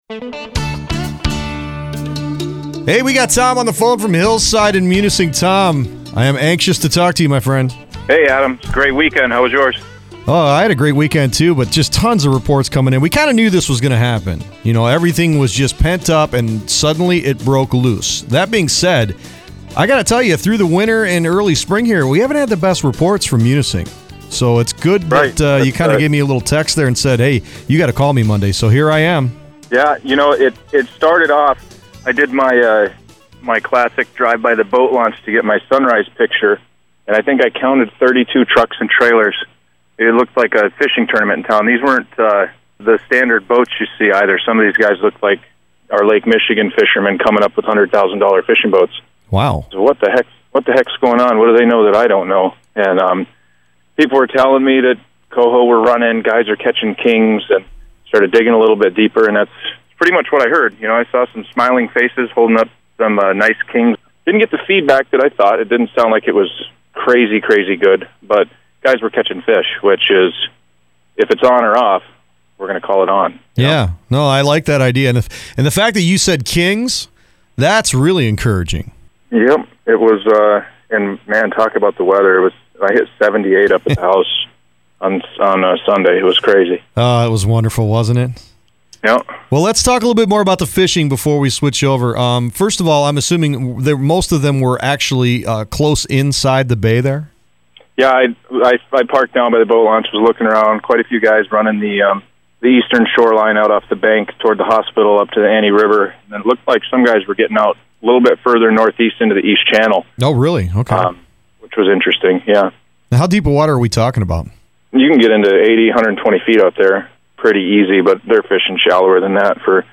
So, we had a chat today over the phone about all the details for fishing in Munising Bay. Plus we talked smelt and turkey scouting in Alger County.